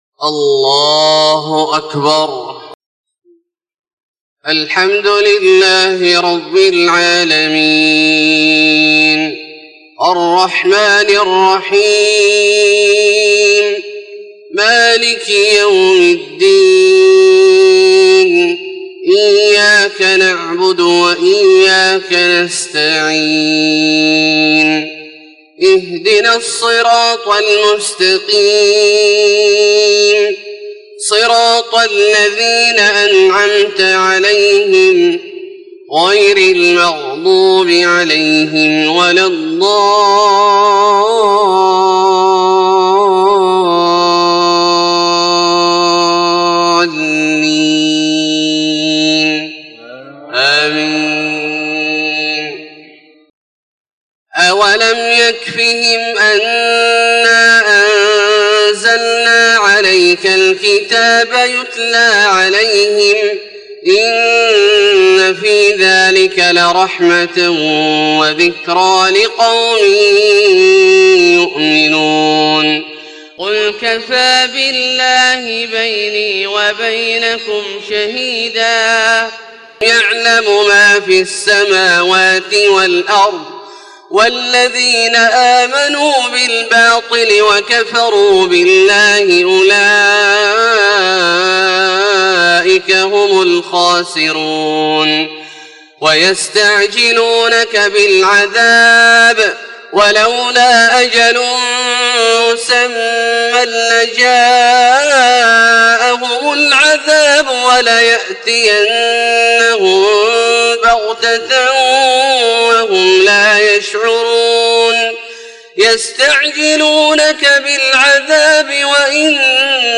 صلاة الفجر 6 - 1 - 1435هـ من سورة العنكبوت > 1435 🕋 > الفروض - تلاوات الحرمين